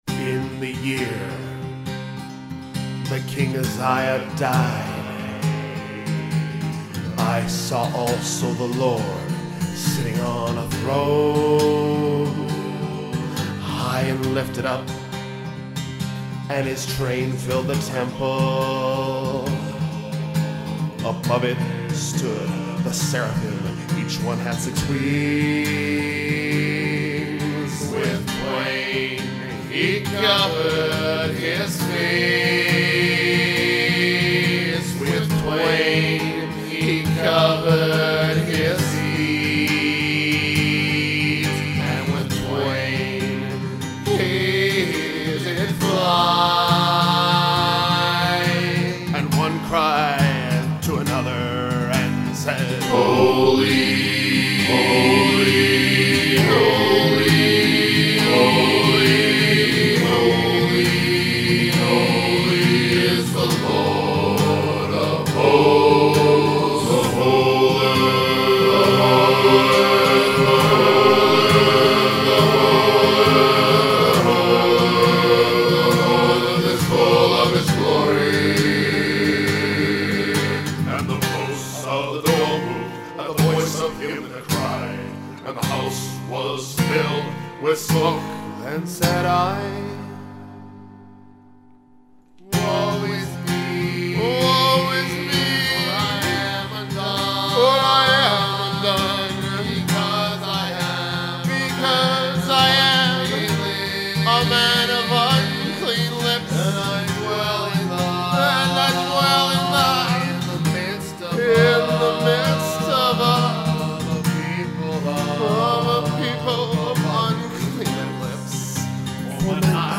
Powerful aggressive folk and rock